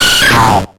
Cri de Kapoera dans Pokémon X et Y.